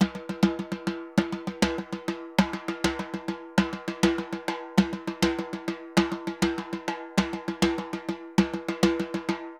Timba_Baion 100_3.wav